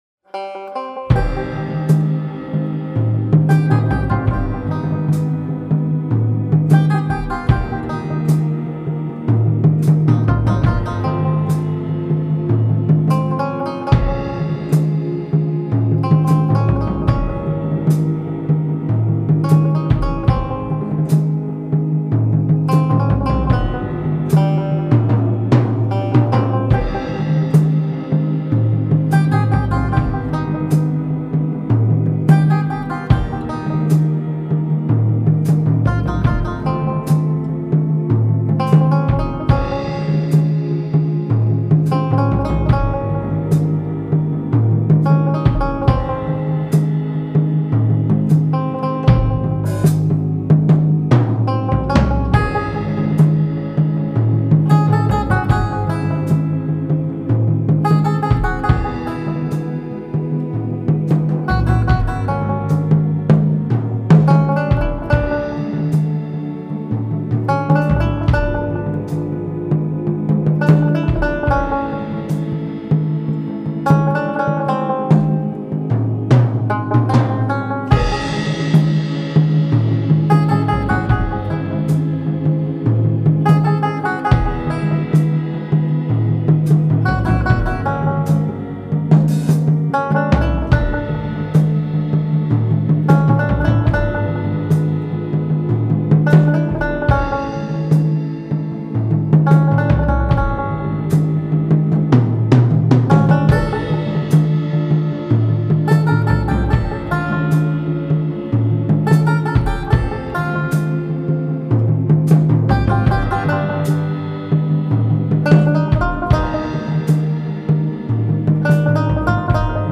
Some spaghetti western music
It's kinda neat-sounding, though, so here it is.